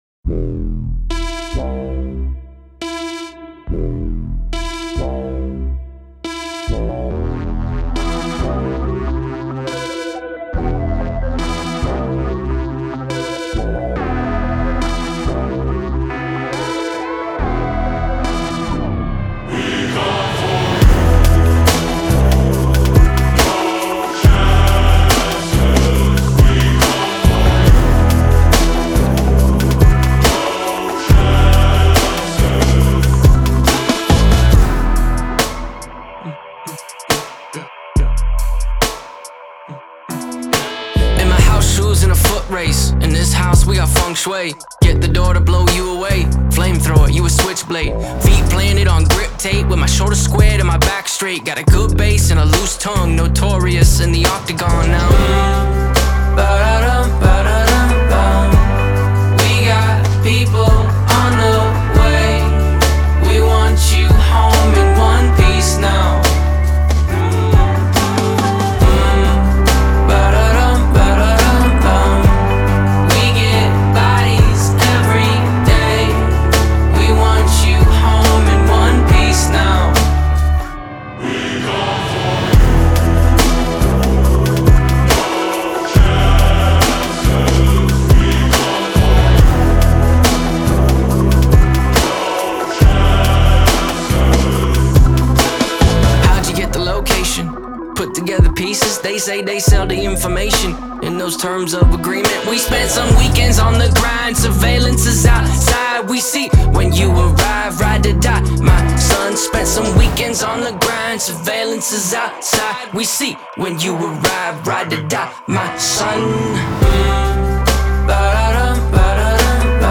Genre : Indie Pop, Alternative